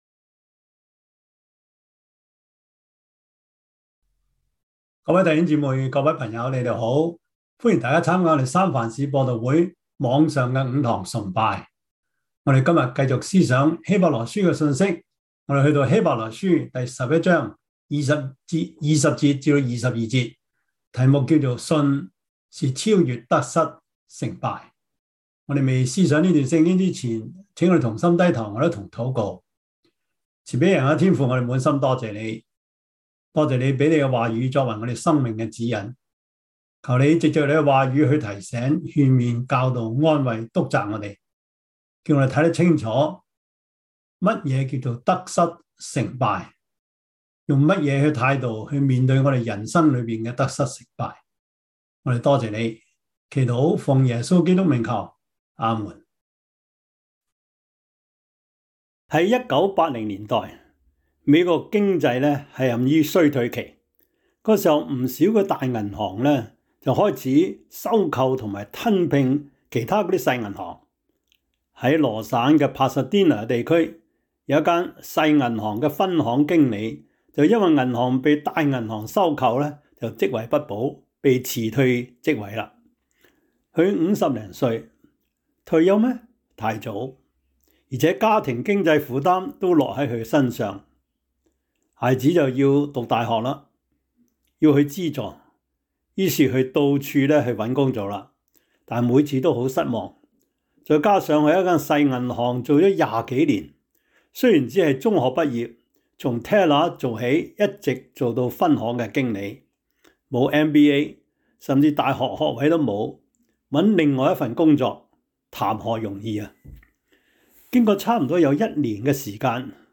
希伯來書 11:20-22 Service Type: 主日崇拜 希 伯 來 書 11:20-22 Chinese Union Version